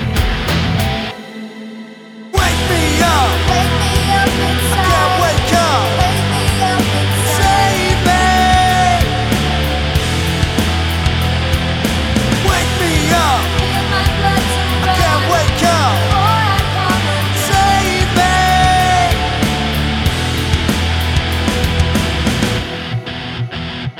For Solo Female Rock 3:48 Buy £1.50